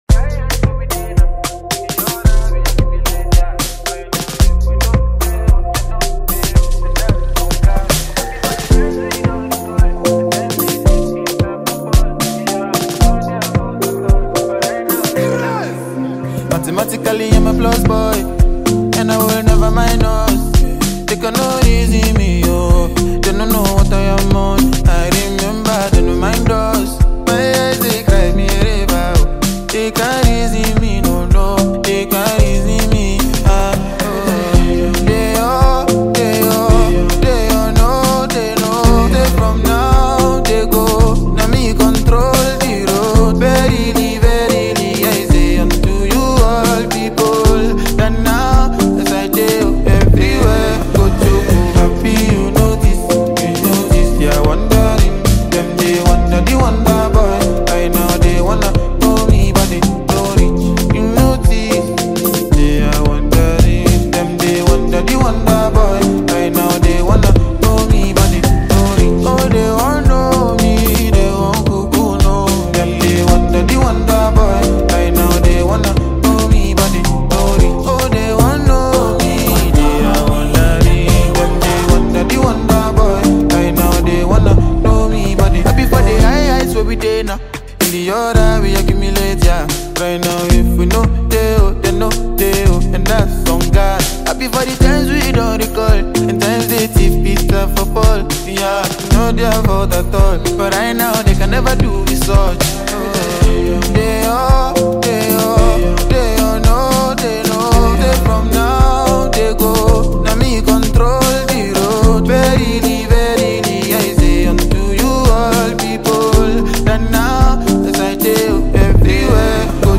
soulful vocals